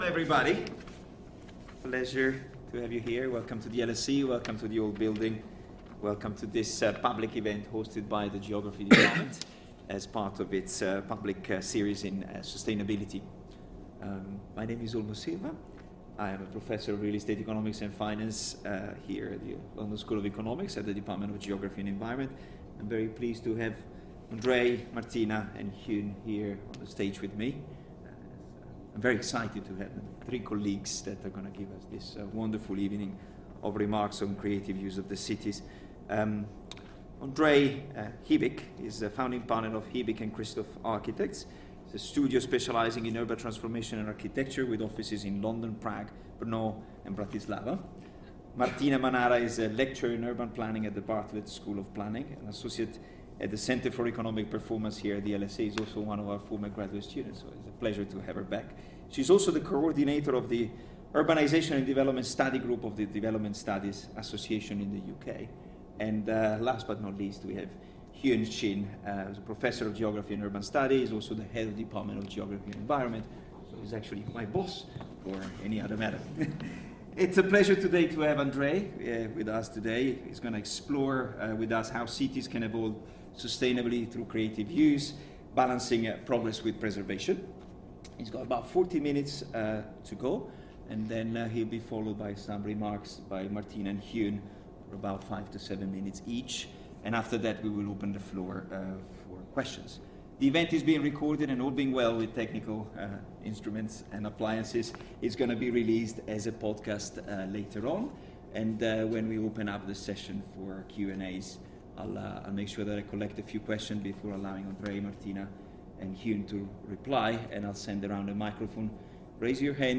Meet our speaker and chair